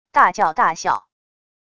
大叫大笑wav音频